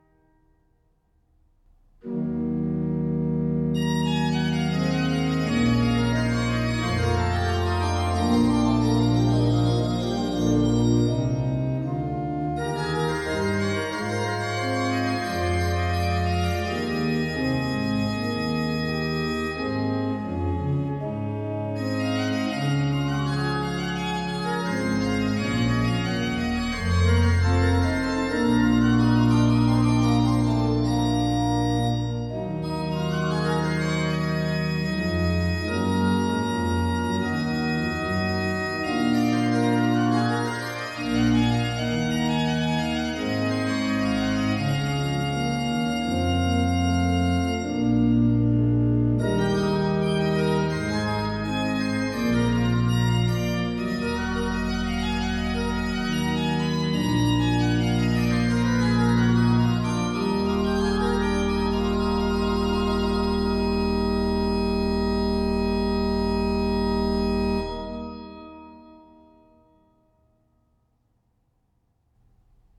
op het historische SCHNITGER orgel (1688) in Norden (D)
Werken voor orgel van Dietrich BUXTEHUDE (1637-1707)